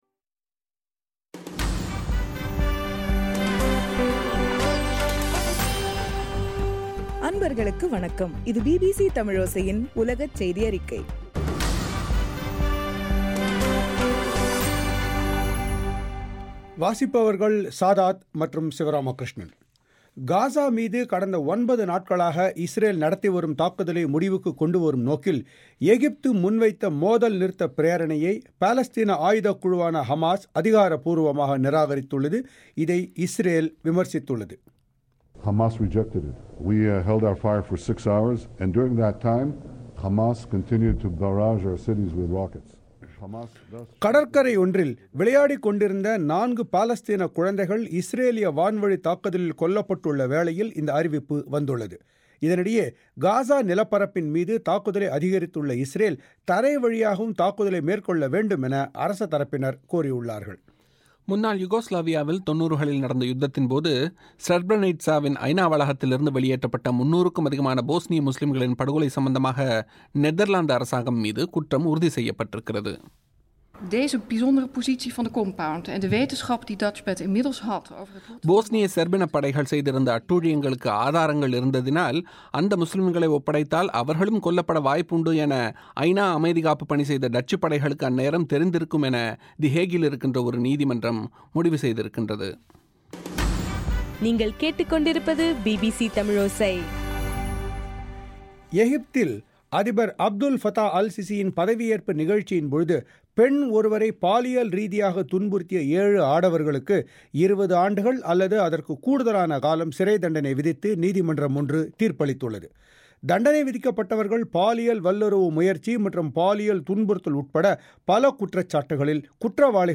ஜூலை 16 2014 பிபிசி தமிழோசையின் உலகச் செய்திகள்